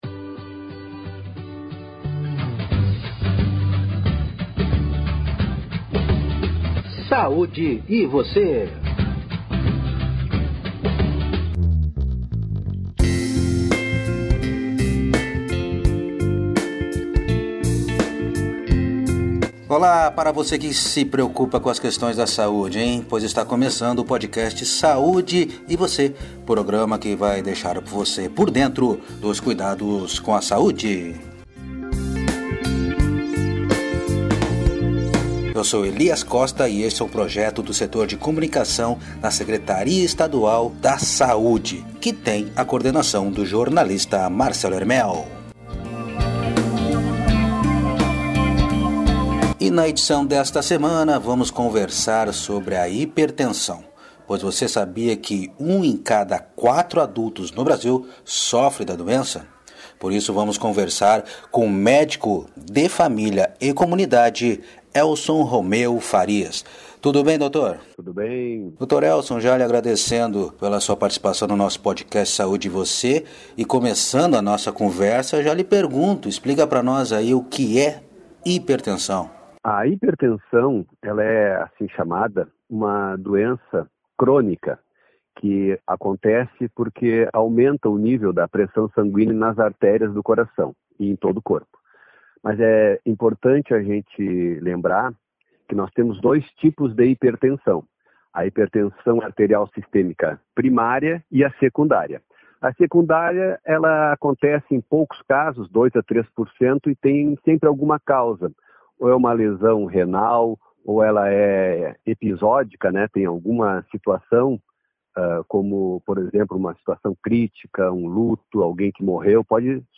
conversamos com o médico de família e comunidade